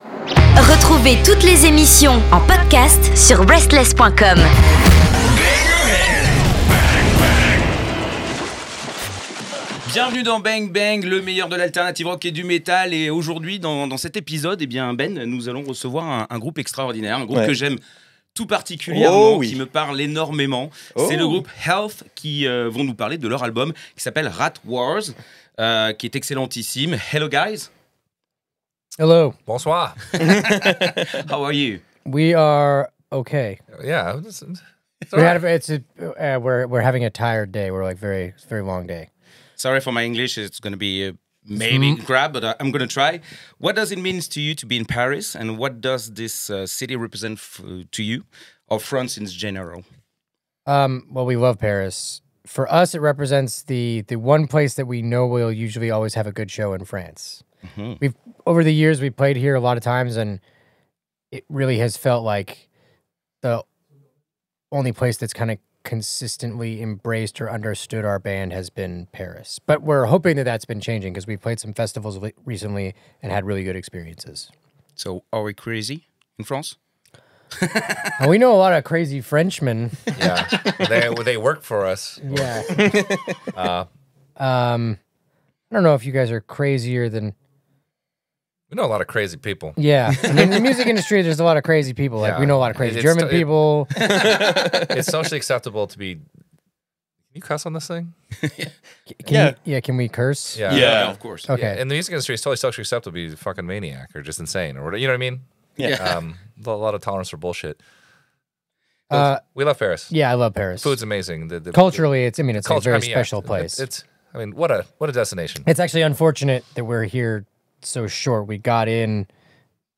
Interview HEALTH